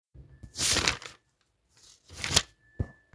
Sound Effects
Page Flip
Page flip.m4a